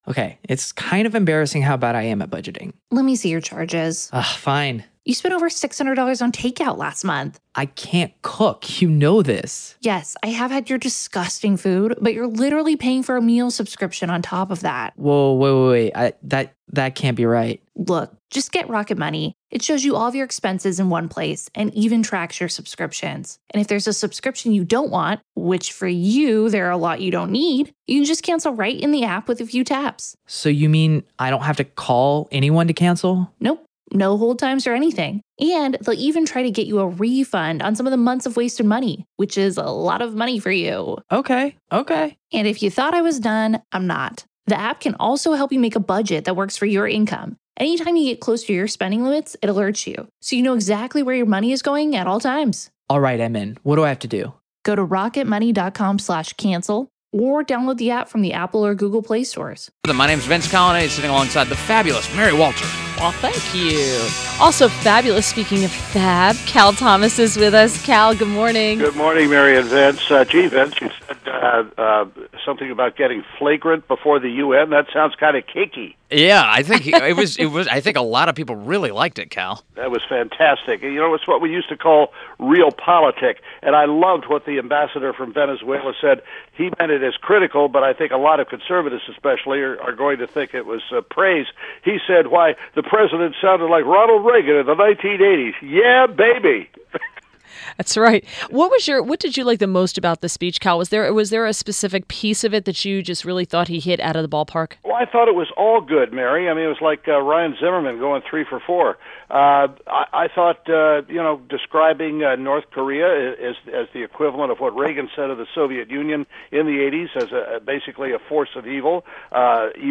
INTERVIEW – CAL THOMAS – syndicated columnist